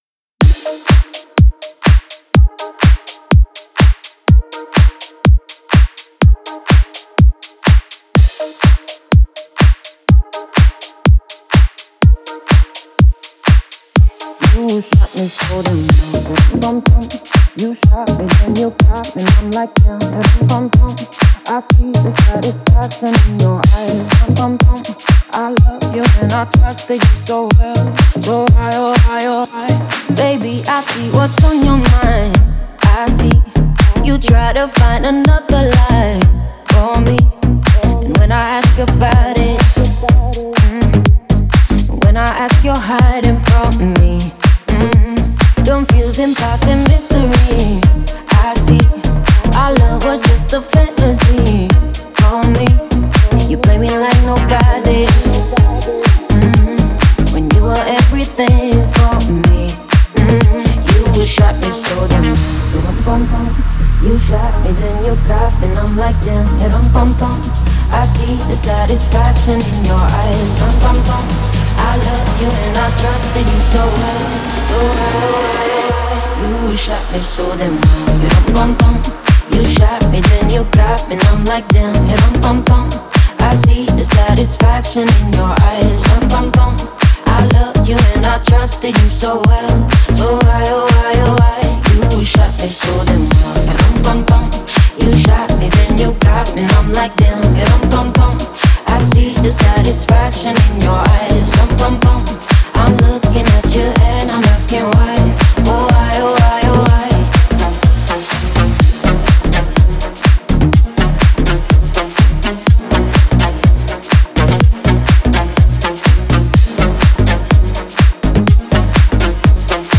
это энергичный трек в жанре поп и EDM